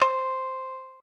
shamisen_c.ogg